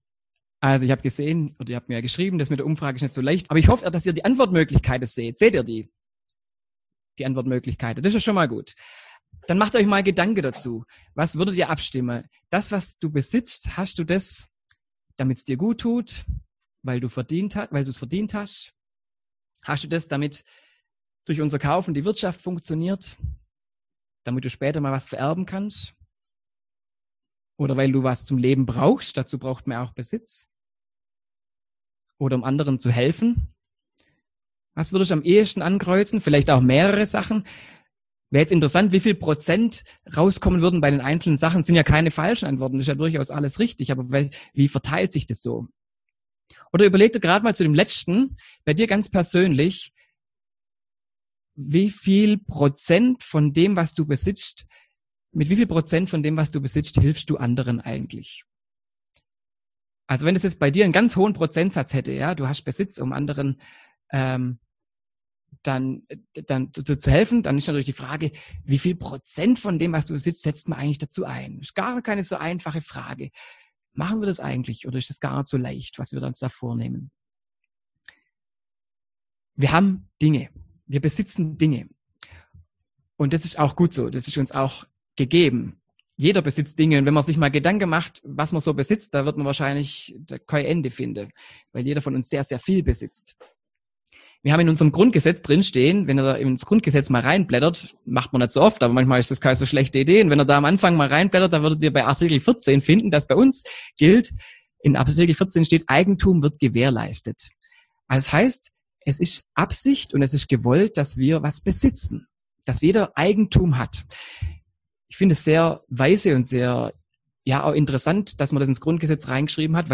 Predigt
im Online-Gottesdienst am 1. Sonntag nach Trinitatis